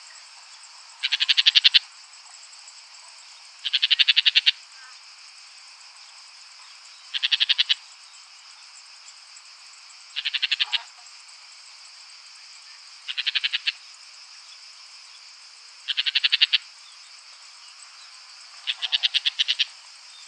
Lanius senator - Woodchat shrike - Averla capirossa